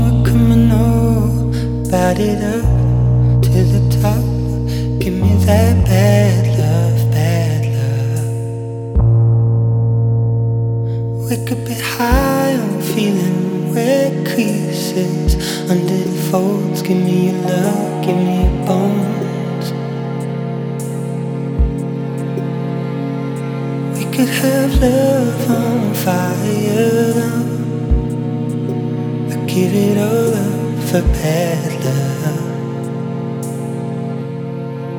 2017-09-20 Жанр: Альтернатива Длительность